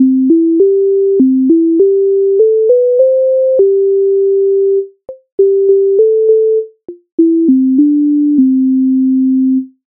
MIDI файл завантажено в тональності C-dur
Попід гай Українська народна пісня зі зб. михайовської
Ukrainska_narodna_pisnia_Popid_haj.mp3